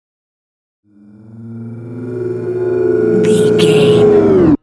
Technologic riser human robot
Sound Effects
Atonal
bouncy
futuristic
intense
tension
riser